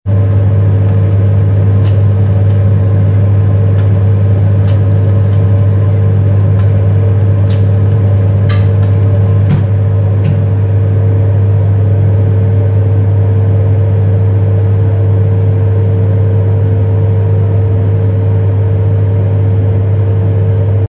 Caldera de gas funcionando
caldera
ruido
Sonidos: Industria
Sonidos: Hogar